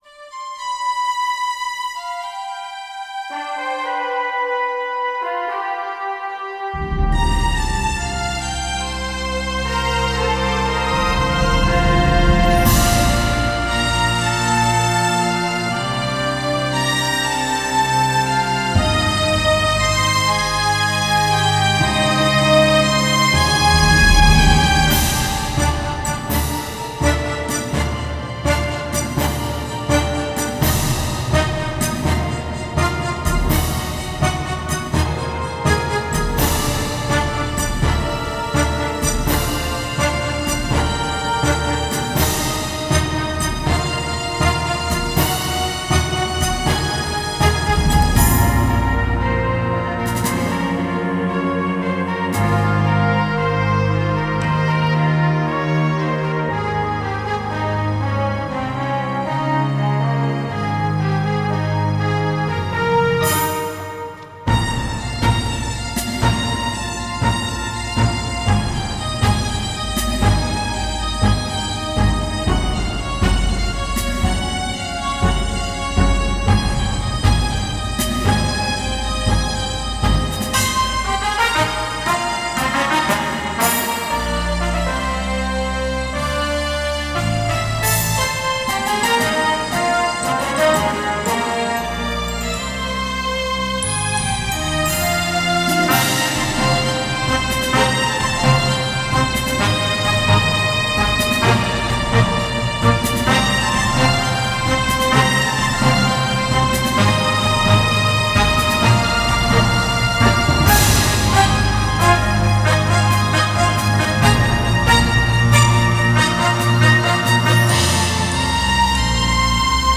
Another orchestral piece. This one uses the full capacity of the SC-880, Korg SG-Rack, and Sound Fonts.